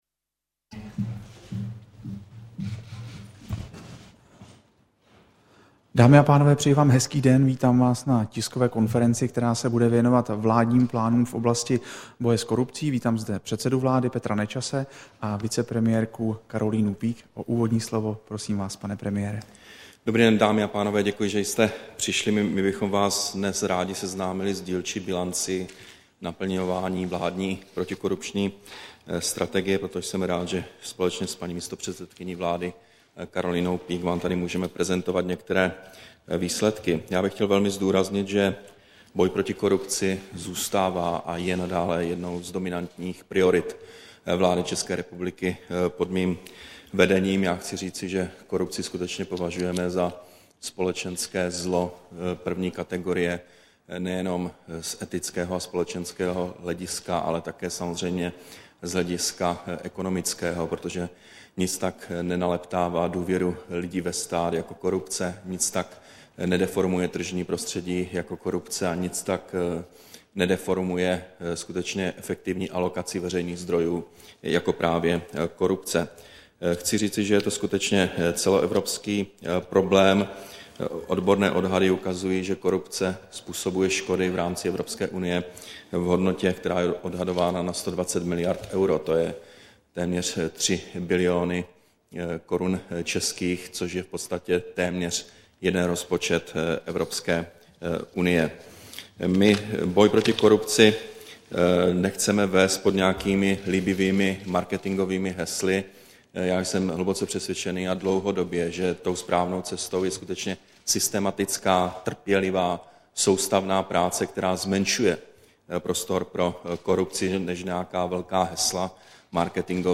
Tisková konference premiéra Petra Nečase a vicepremiérky Karolíny Peake k představení plánů vlády v oblasti boje s korupcí, 14. února 2012